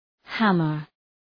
hammer Προφορά
{‘hæmər}